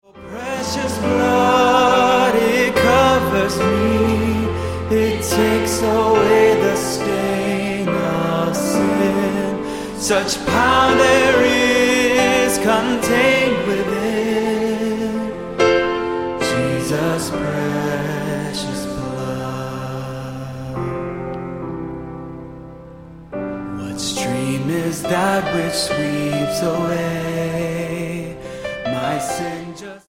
Neu und live aufgenommen
Lobpreis-Hits
• Sachgebiet: Praise & Worship